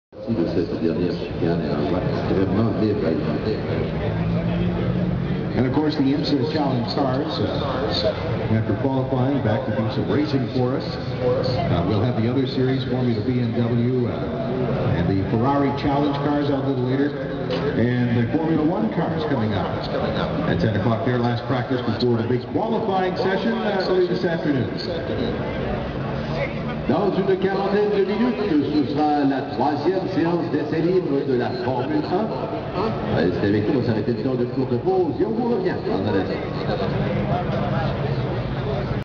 I also have a couple of examples I recorded in Montreal last June of this kind of thing. The spin out example was of a Porsche 911 race car. Most of the time the crowd is silent, if not quiet.